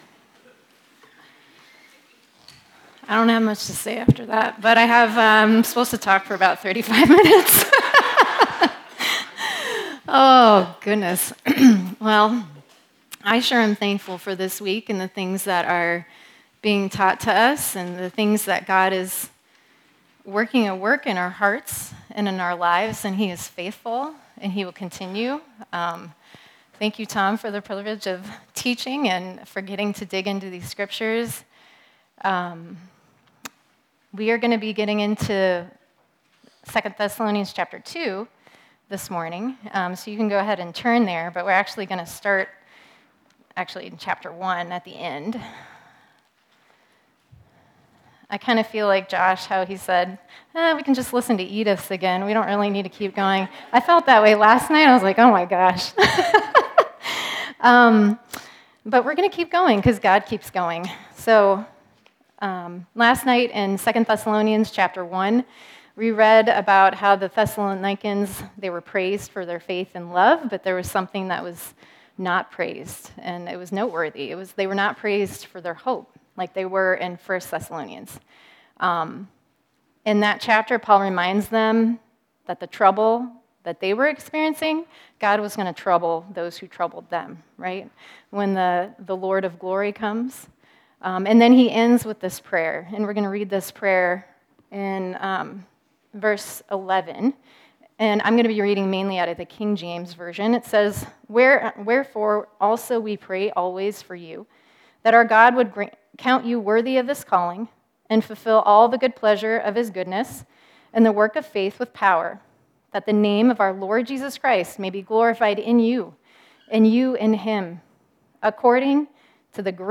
Part 11 in a verse-by-verse teaching series on 1 and 2 Thessalonians with an emphasis on how our hope helps us to live holy lives until Christ returns.
2 Thessalonians 2:1-12 Our Daily Hope (Family Camp 2024) – Part 11 August 1, 2024 Part 11 in a verse-by-verse teaching series on 1 and 2 Thessalonians with an emphasis on how our hope helps us to live holy lives until Christ returns.